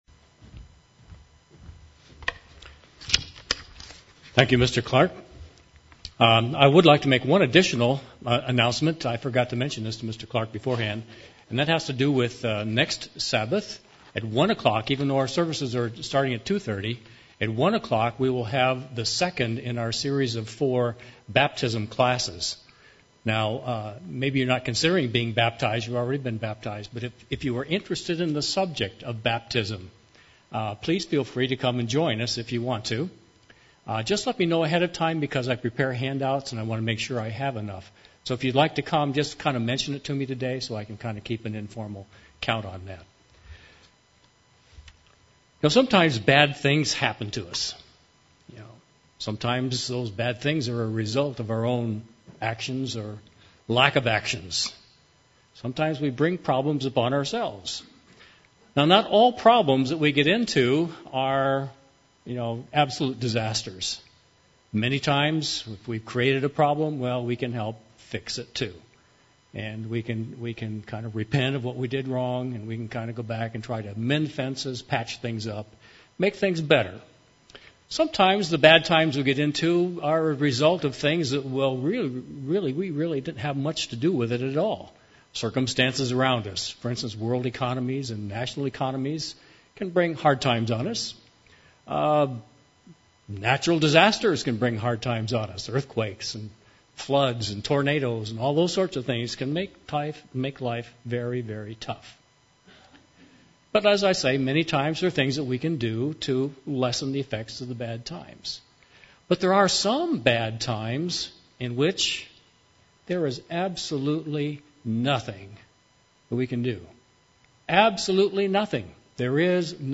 UCG Sermon Transcript See the Salvation of the Lord Read more Studying the bible?